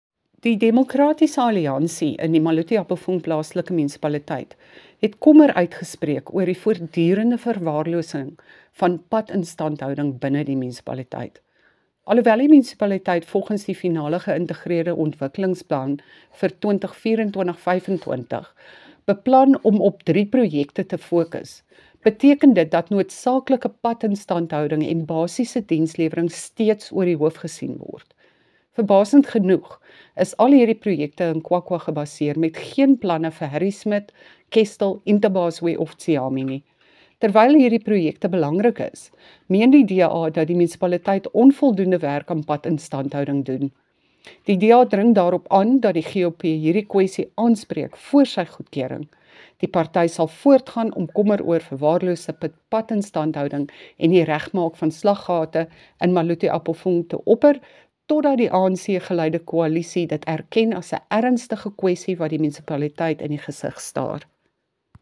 Afrikaans by Cllr Bea Campbell-Cloete.